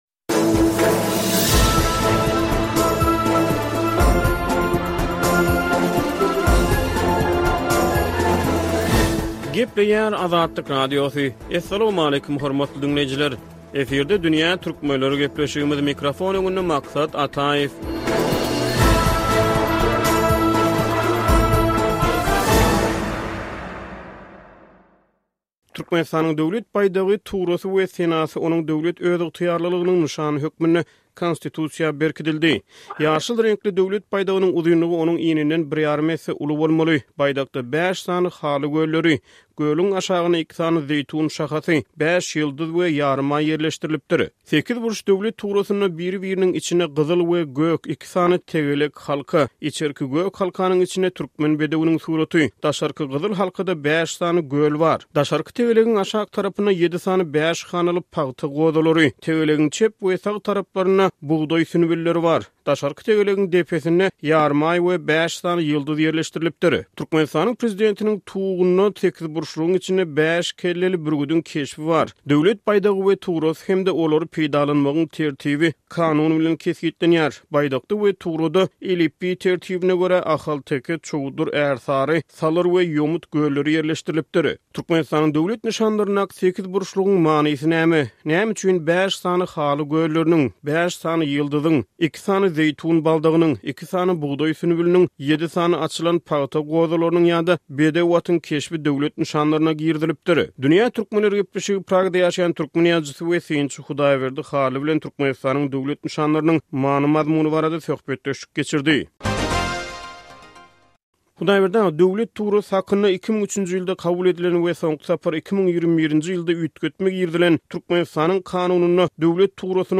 söhbetdeşlik